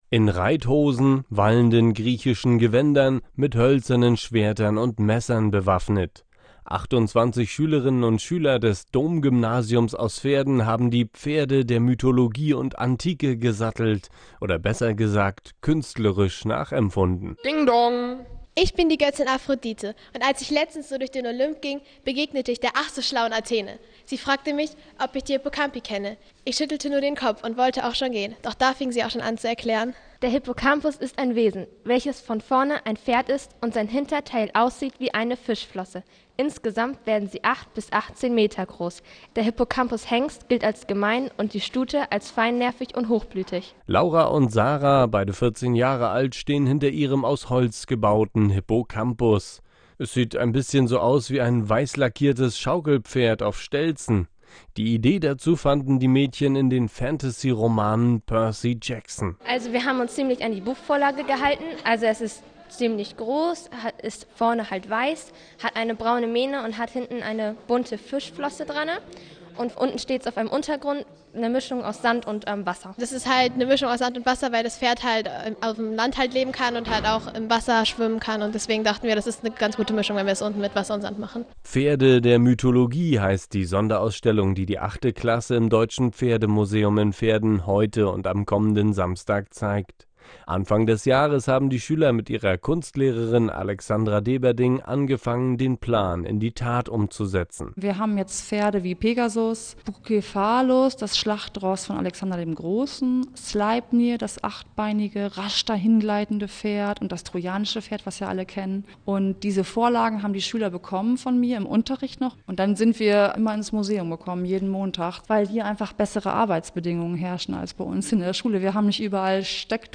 Bericht im Nordwestradio